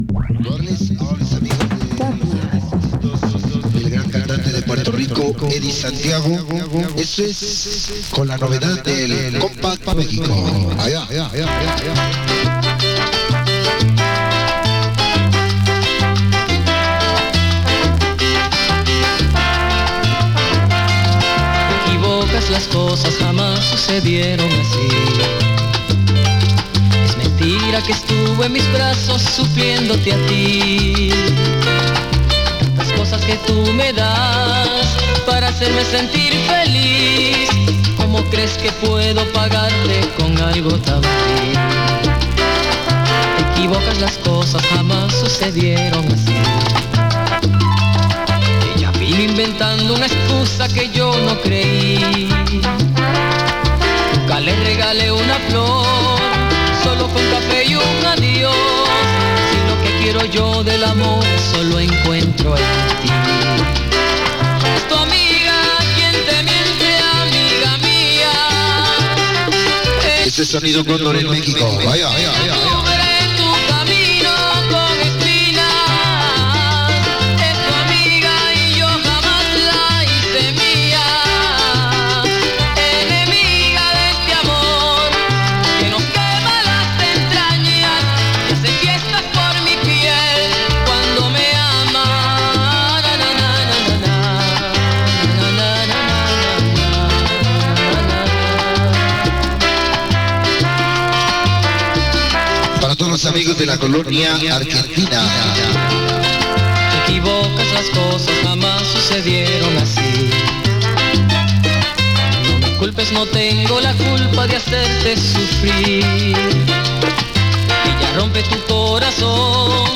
Latin New Wave Pop Rock Soul